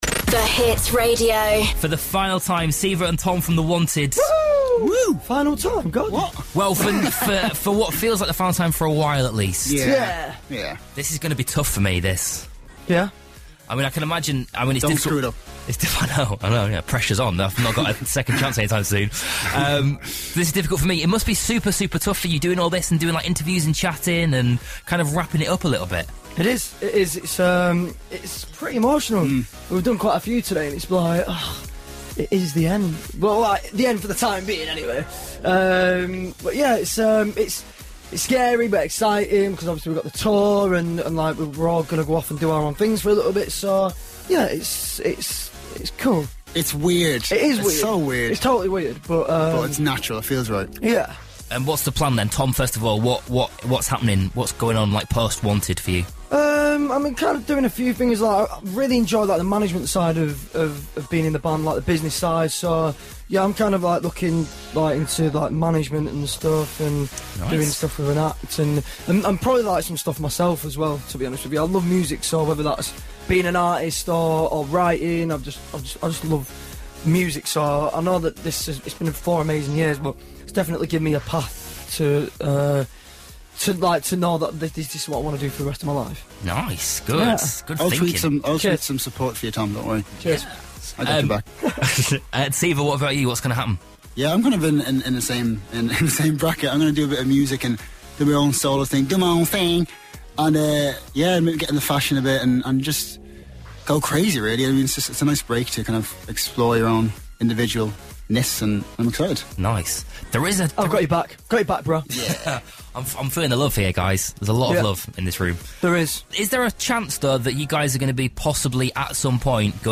The Wanted's Final Interview
Tom and Siva popped by The Hits Radio to say goodbye and take calls on The Wanted Helpline.